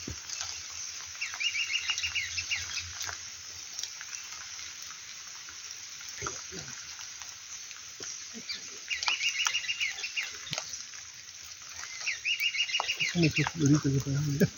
Bailarín Castaño (Piprites pileata)
Nombre en inglés: Black-capped Piprites
Localización detallada: Camino rural sin nombre
Condición: Silvestre
Certeza: Fotografiada, Vocalización Grabada